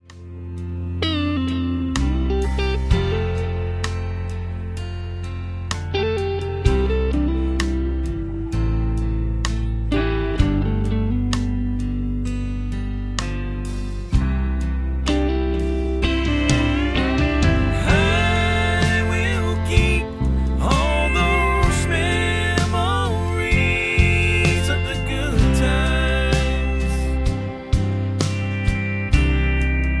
Buy With Lead vocal (to learn the song).
MPEG 1 Layer 3 (Stereo)
Backing track Karaoke
Country, 2010s